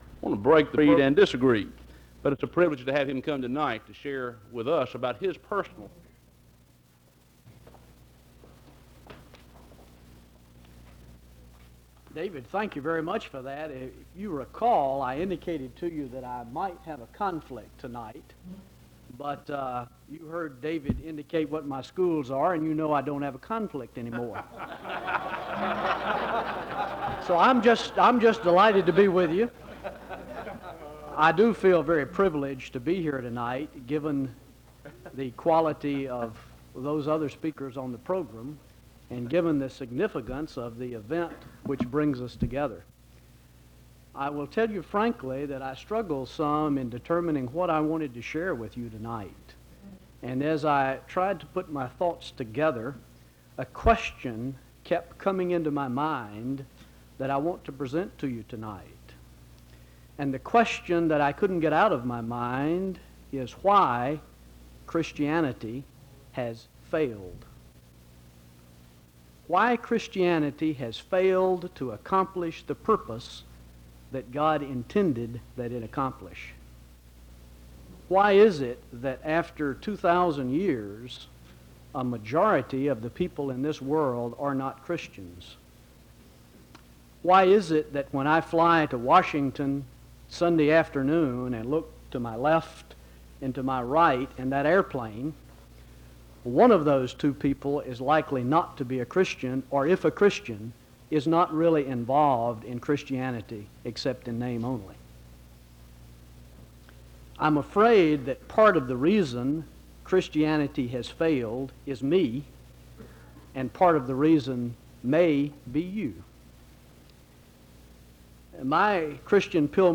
SEBTS Convocation of the Laity March 22 to 24, 1985
The service is concluded in a word of prayer (1:49:37-1:50:32).